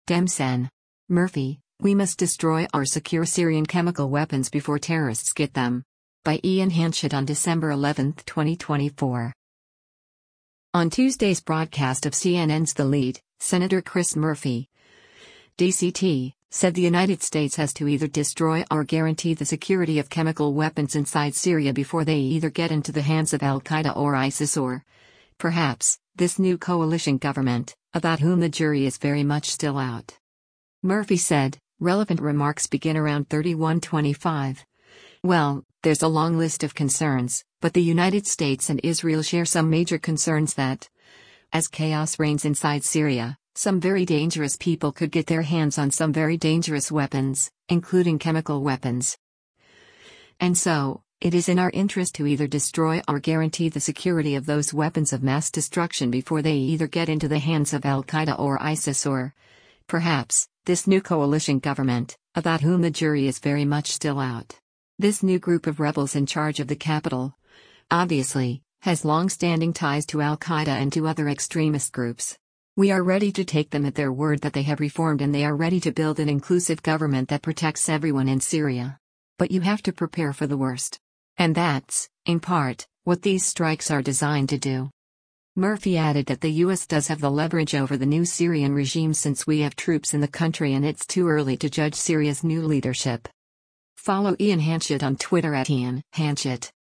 On Tuesday’s broadcast of CNN’s “The Lead,” Sen. Chris Murphy (D-CT) said the United States has to “either destroy or guarantee the security of” chemical weapons inside Syria “before they either get into the hands of al-Qaeda or ISIS or, perhaps, this new coalition government, about whom the jury is very much still out.”